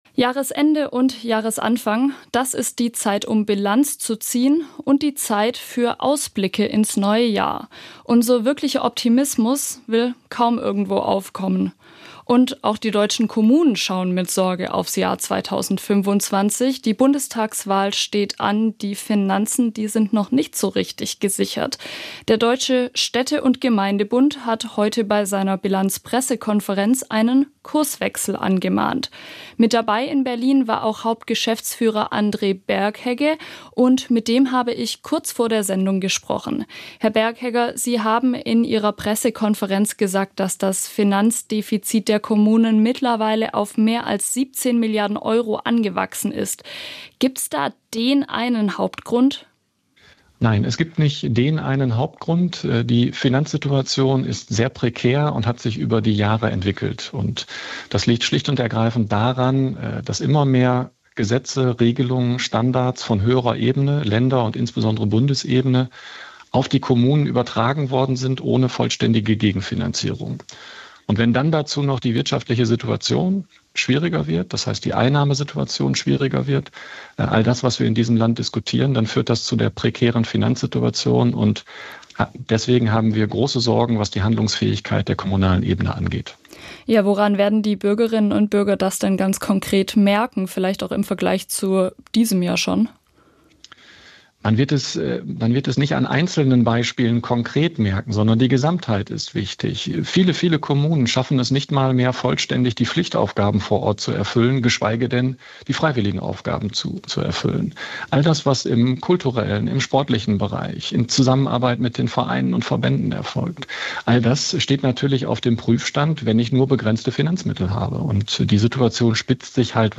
im Gespräch mit SWR Aktuell-Moderation